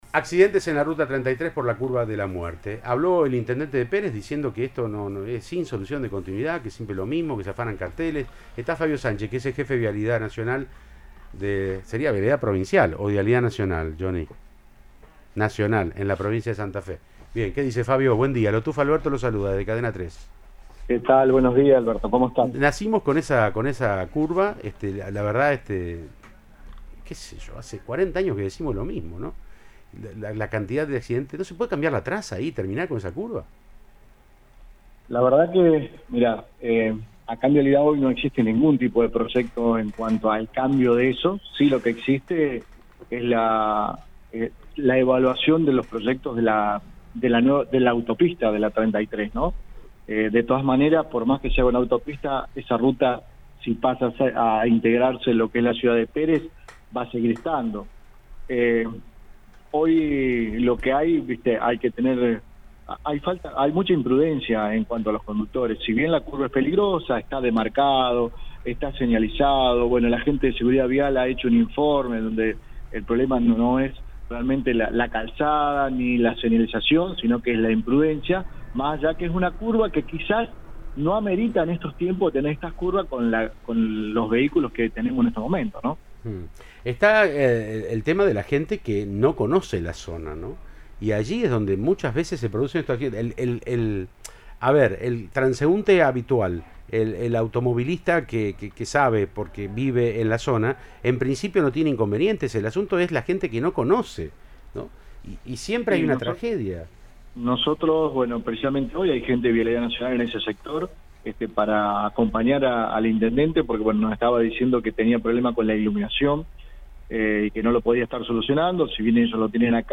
Hay mucha imprudencia de los conductores”, dijo a Siempre Juntos, por Cadena 3 Rosario, al tiempo que afirmó que según informes profesionales “el problema no es la calzada ni la iluminación”, sino la conducta de los choferes.